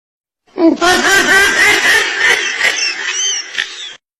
Risada Atumalaca slowed
Categoria: Risadas
Com uma risada icônica e cheia de energia, esse áudio vai fazer todo mundo rir e entrar no clima da diversão.
risada-atumalaca-slowed-pt-www_tiengdong_com.mp3